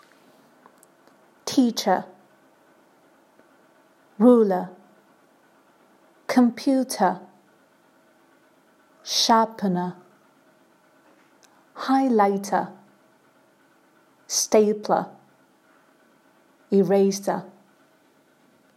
Listen to the pronunciation of the words in the box.